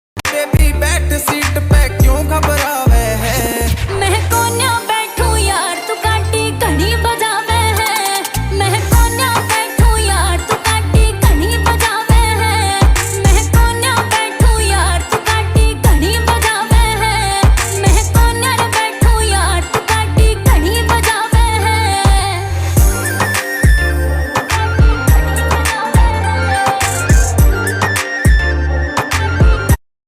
Trending Punjabi Ringtone